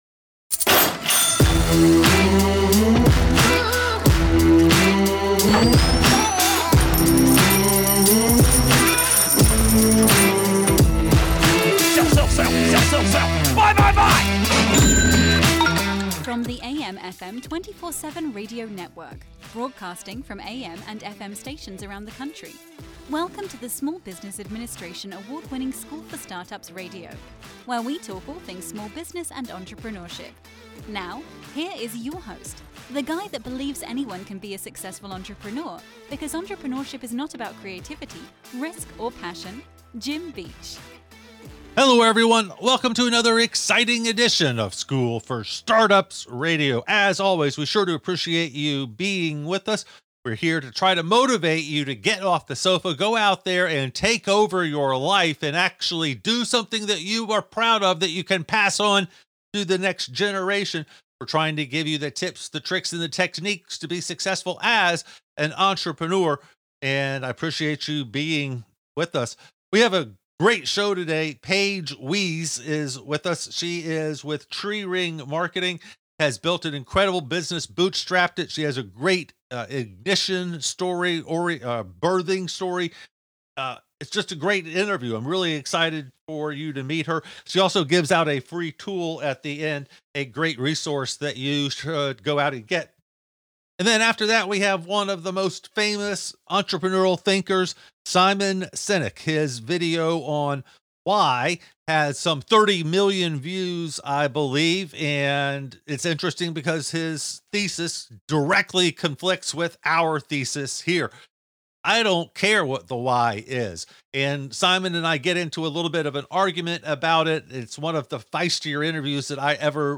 talks to some of the top entrepreneurs from around the world